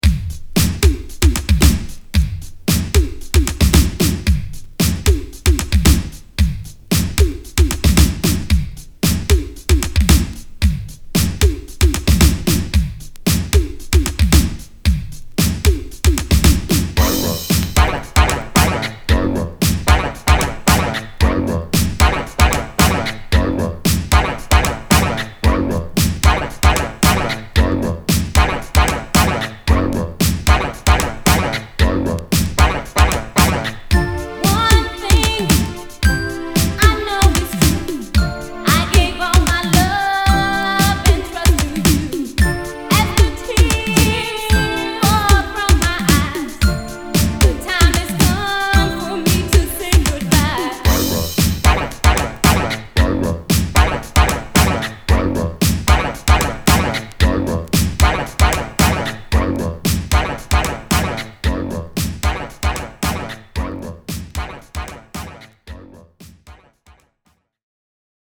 LS 80's NY Electro Disco!!